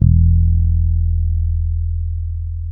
-MM DUB  E 2.wav